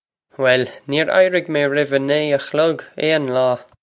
Pronunciation for how to say
Well, neer eye-rig may rev ah nee ah khlug ayn law!
This is an approximate phonetic pronunciation of the phrase.